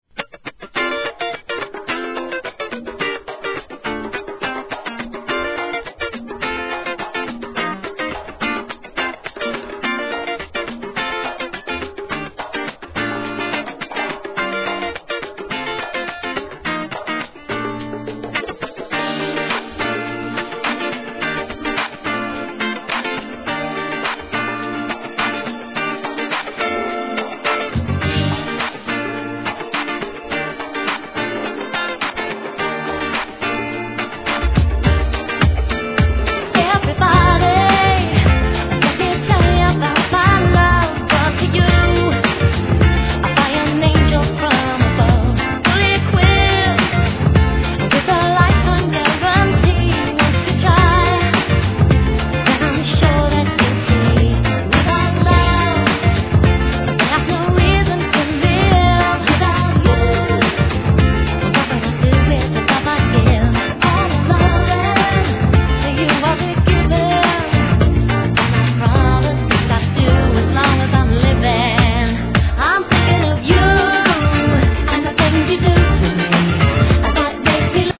HIP HOP/R&B
人気カヴァー・シリーズの第1弾!!